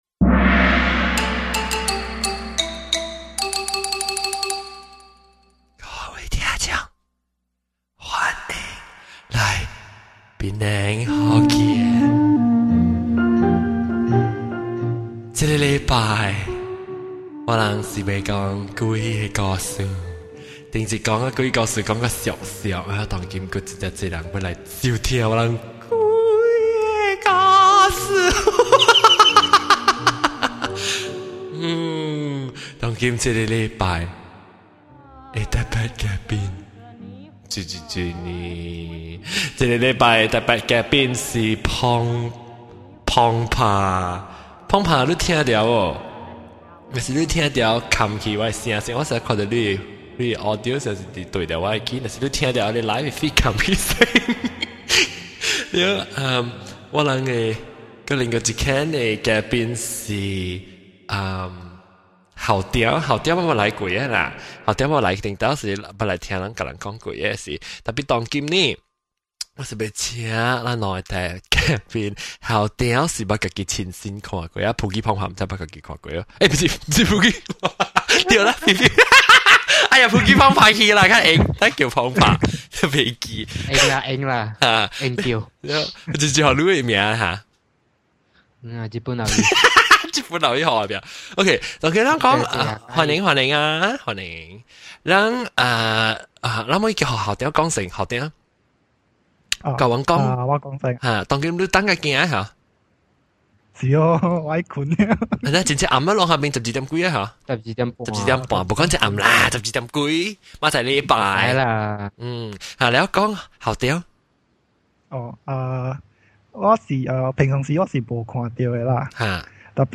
Almost everyone enjoys sharing and listening to ghost stories, I will try to make it a monthly deal. This month, we are joined by a few people who experienced ghost encounters themselves. We will get stories right from Singapore’s military training ground.